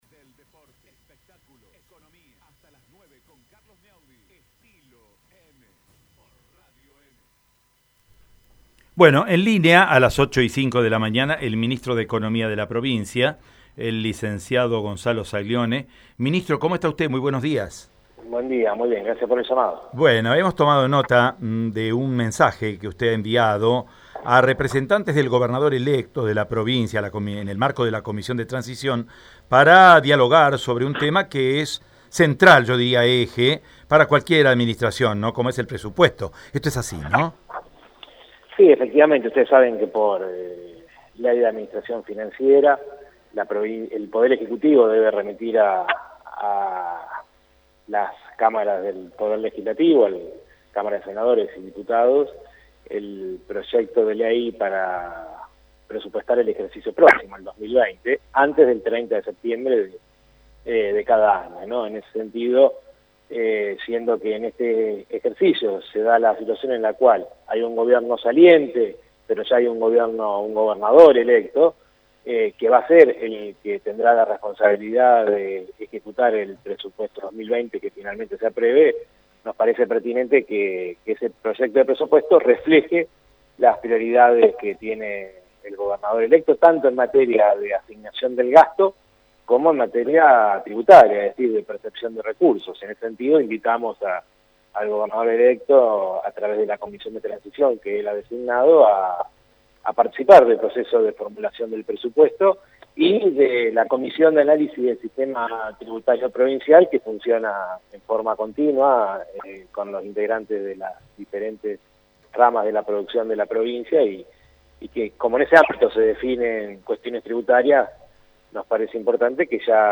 nota-gonzalo-saglione-ministro-de-economia.mp3